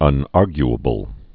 (ŭn-ärgy-ə-bəl)